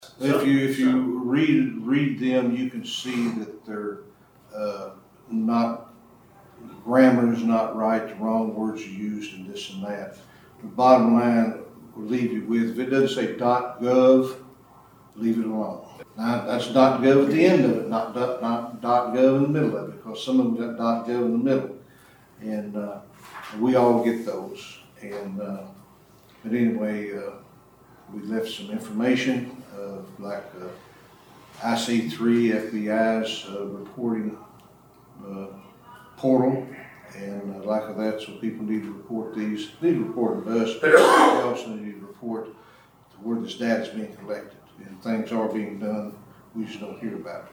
At a special-called Fiscal Court meeting Friday morning, Sheriff Don Weedman explained that you can identify scam text messages by the unusual or suspicious wording they use.